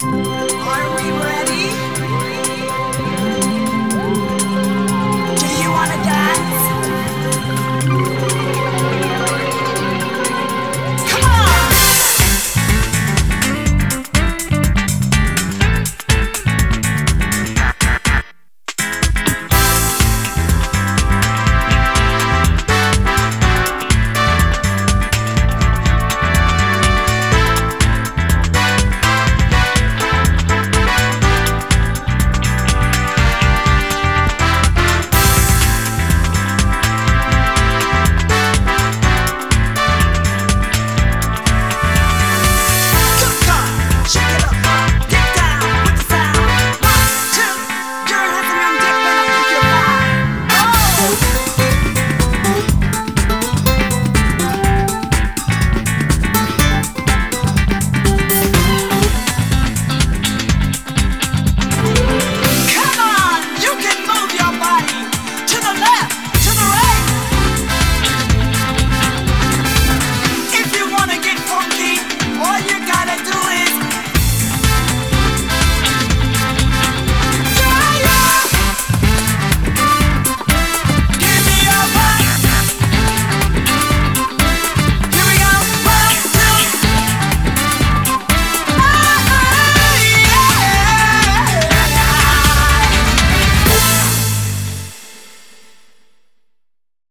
BPM123
Better quality audio.